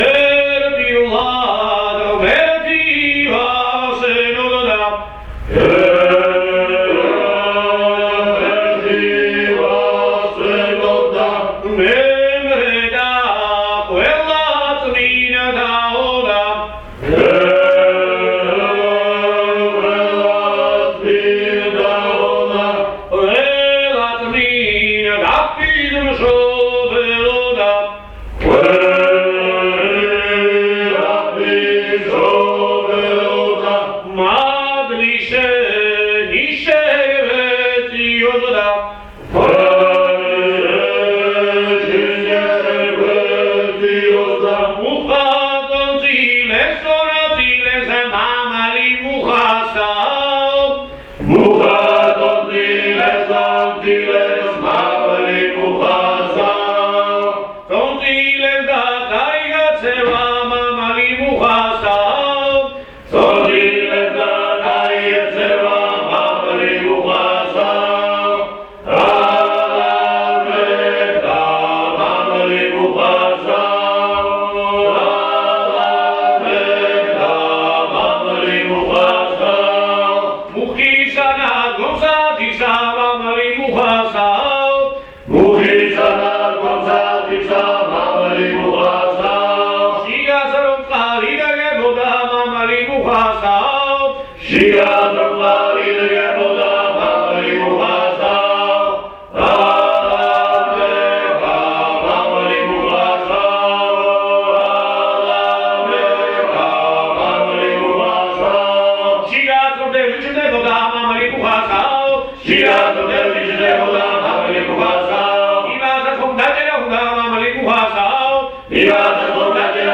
合唱団「メスヘティ」による男声三部合唱（アハルツィヘ市）